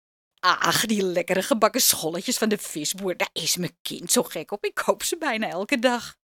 Hieronder wat stemvoorbeelden